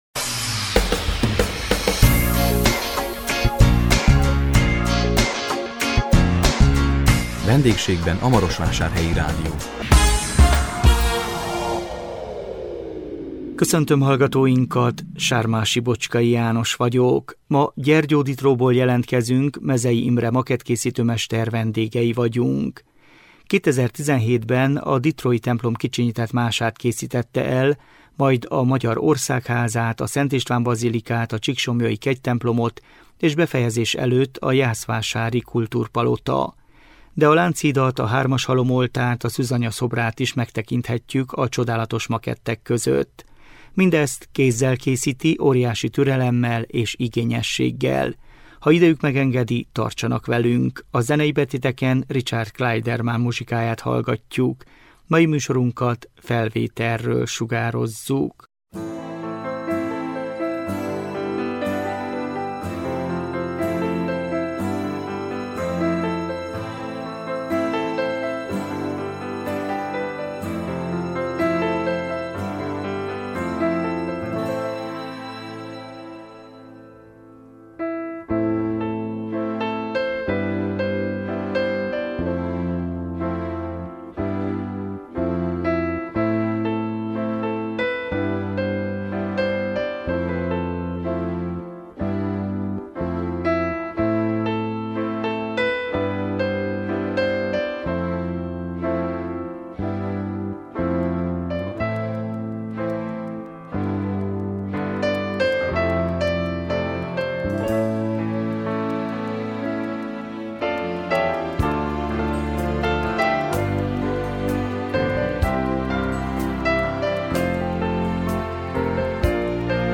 Gyergyóditróból jelentkezünk